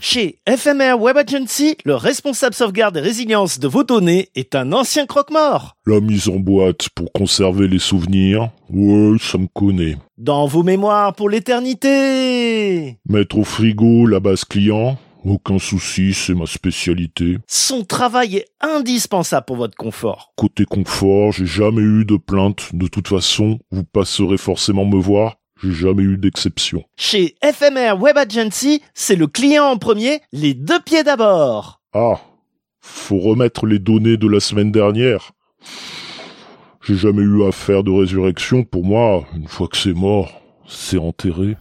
Fausse publicité : Responsable back-up croquemort